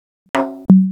New Bassline Pack